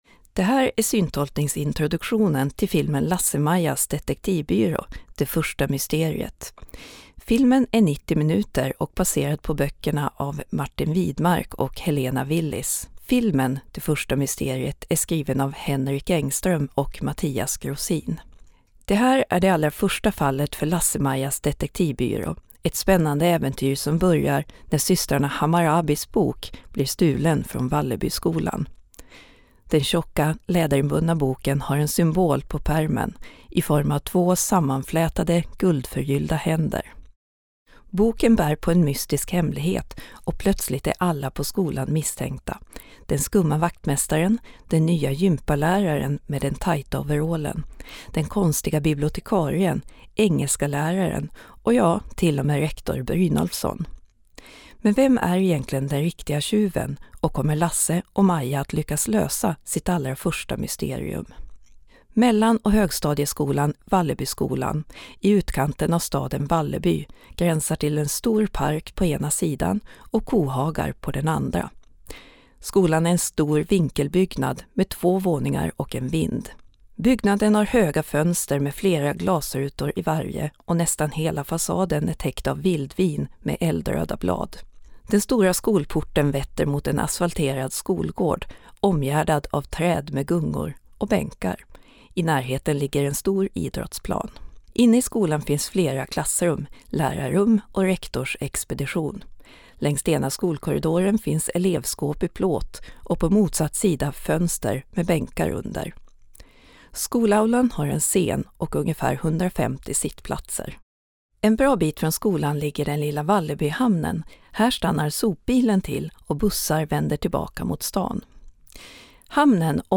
Syntolkningen kommer finnas på mobilappen för bio och senare även TV/streaming. Hur gick det egentligen till när Lasse och Maja träffades för första gången?